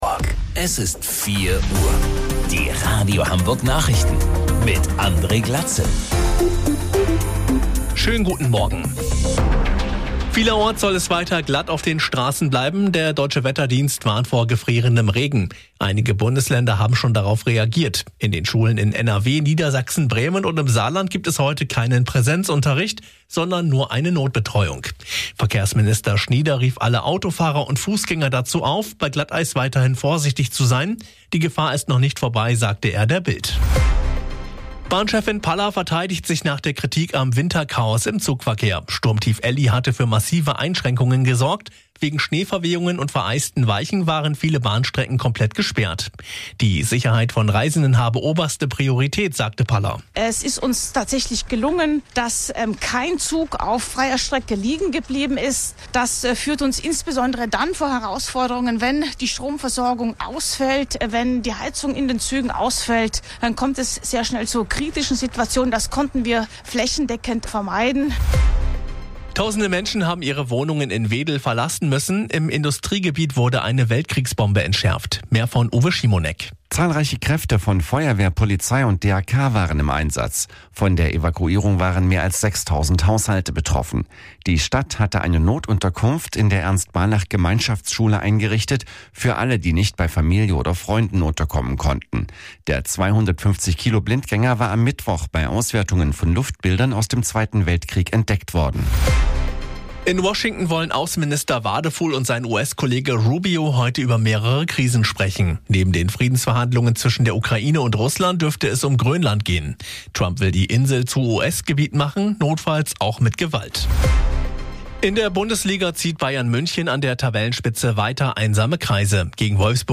Radio Hamburg Nachrichten vom 12.01.2026 um 04 Uhr